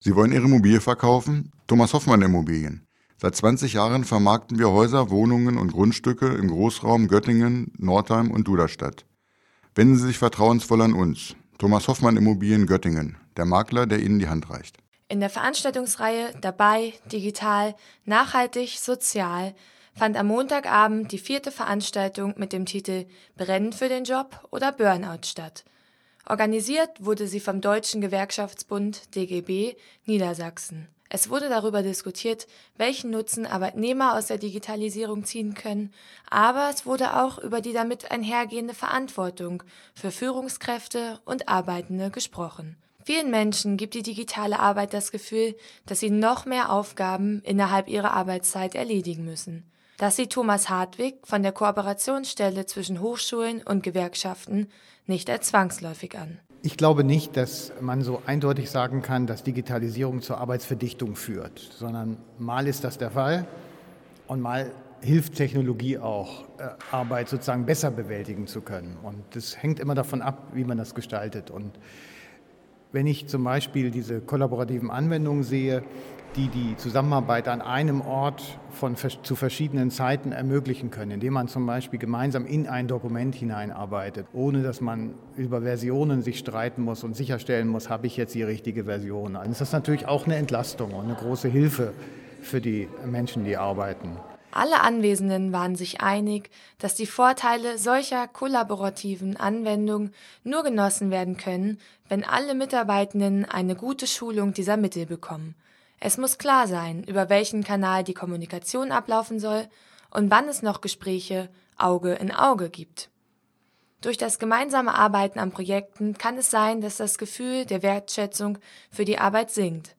Bringen die vielen Möglichkeiten wirklich Entlastung oder sind sie der eigentliche Ursprung der Stressbelastungen, über die viele Menschen klagen? Am Montagabend fand eine Diskussionsveranstaltung in der Alten Mensa in Göttingen statt, die sich diesen und ähnlichen Fragen widmete.